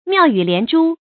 妙语连珠 miào yǔ lián zhū 成语解释 连珠：串珠；像珠子一样一个接一个串接着。